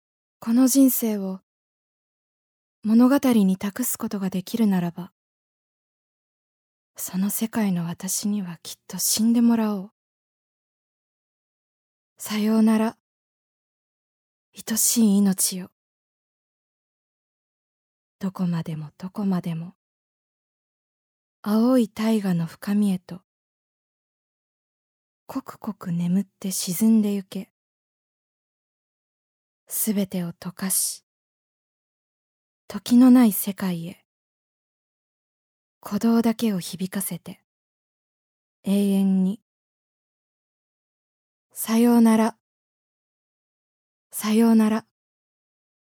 ボイスサンプル
朗読